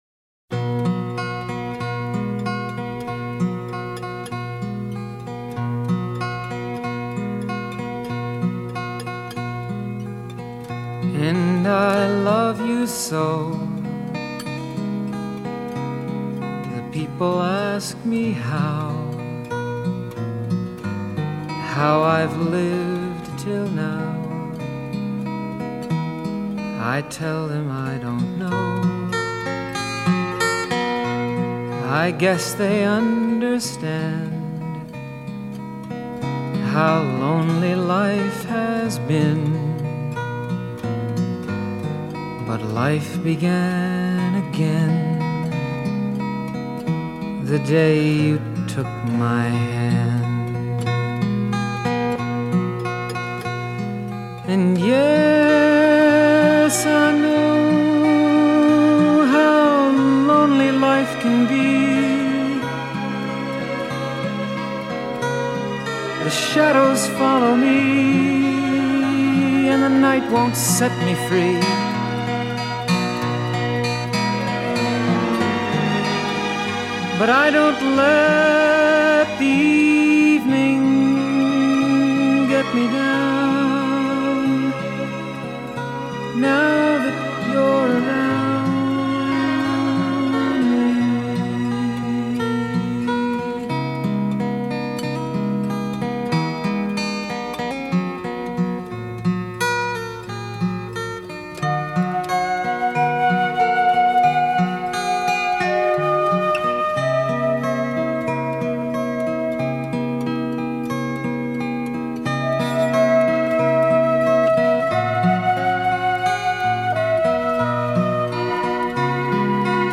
喜欢这种冥想式的音乐～～～～